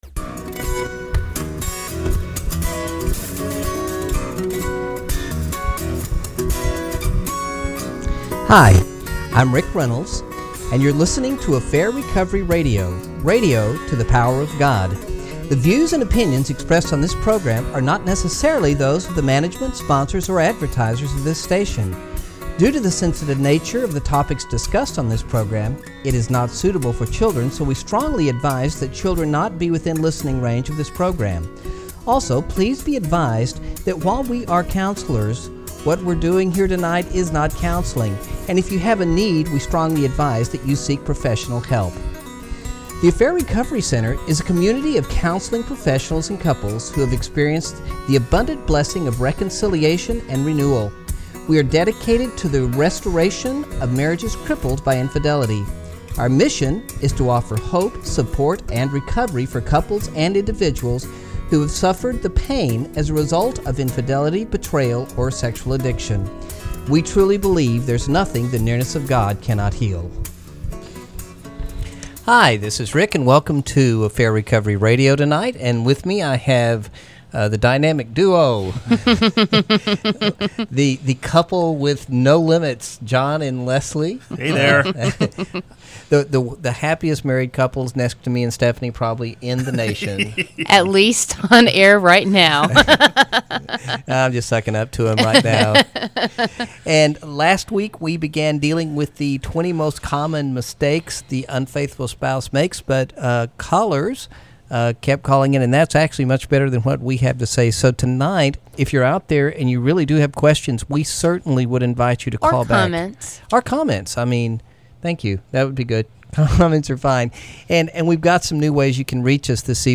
They finish the list of Top 10 Mistakes of the unfaithful spouse and help a caller too.